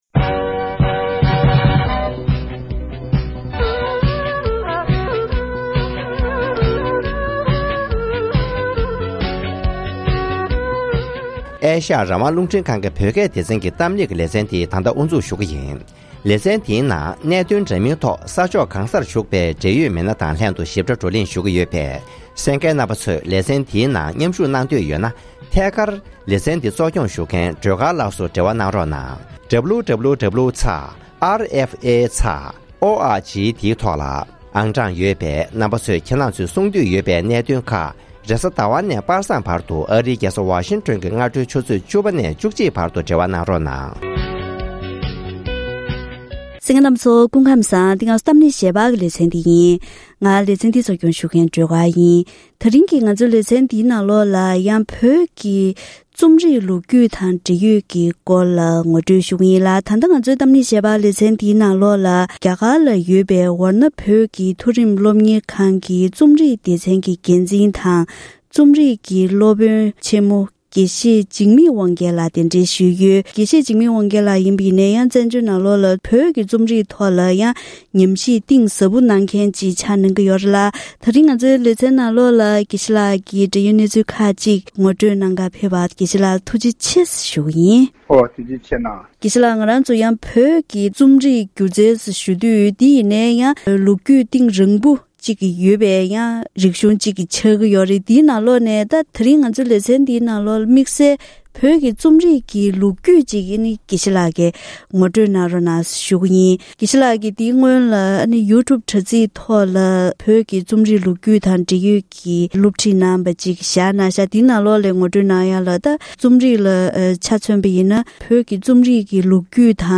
༄༅༎དེ་རིང་གི་གཏམ་གླེང་ཞལ་པར་ལེ་ཚན་ནང་བོད་ཀྱི་རྩོམ་རིག་གི་ལོ་རྒྱུས་ཐོག་ནས་བོད་བཙན་པོའི་སྐབས་ཀྱི་རྩོམ་རིག་གི་རིན་ཐང་དང་ནུས་པ།